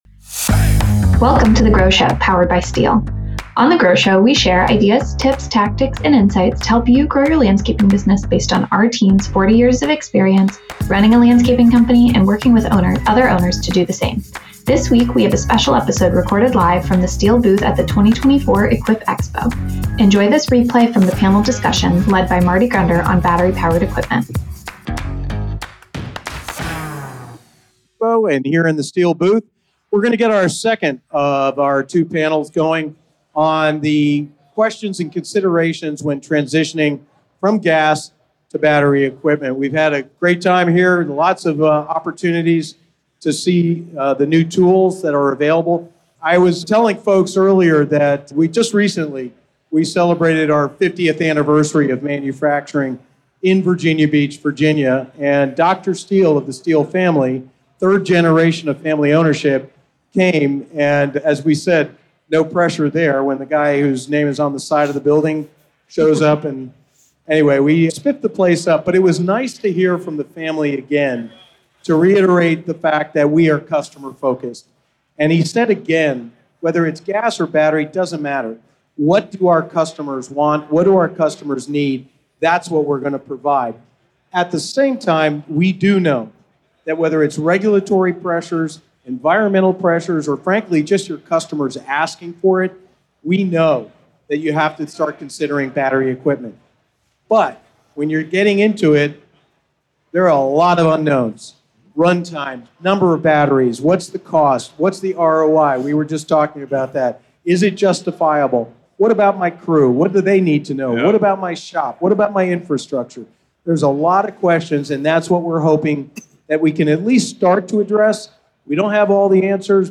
Interview Series: Managing Battery Power All Day Long LIVE from Equip Expo